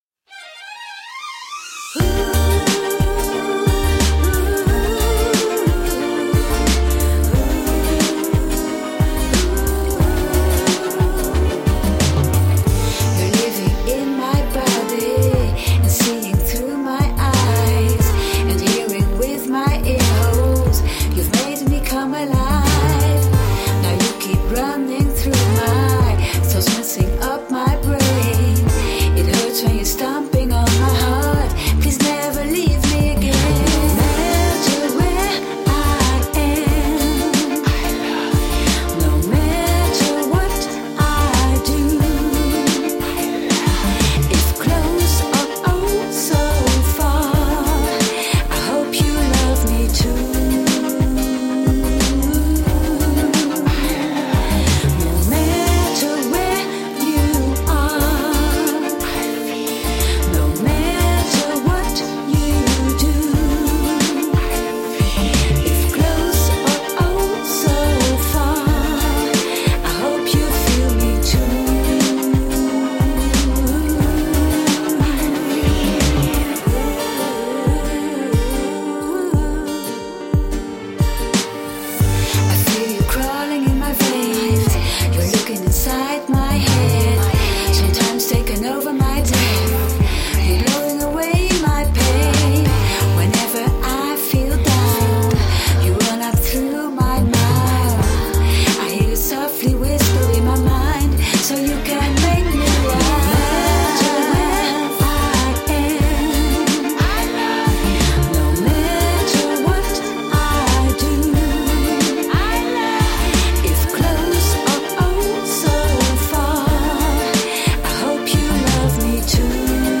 Positive vibe electro pop.
Tagged as: Electro Rock, Other